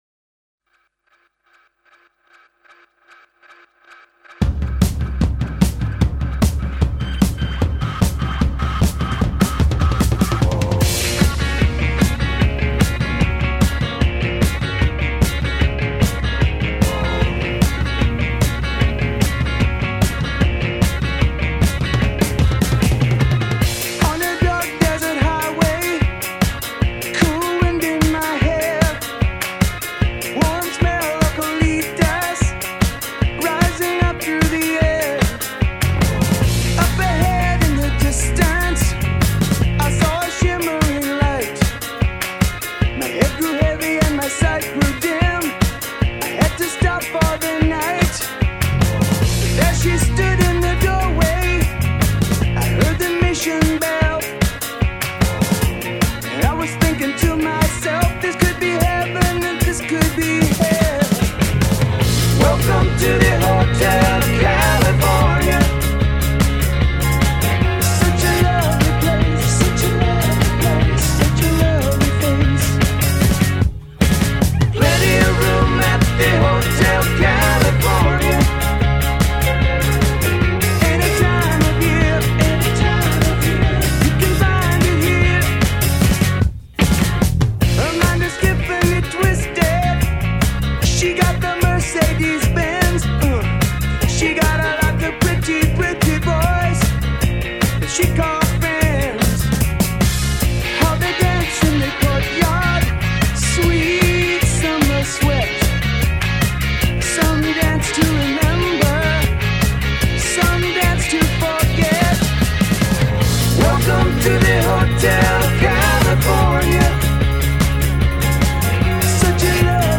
Bootleg